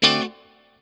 CHORD 7   AE.wav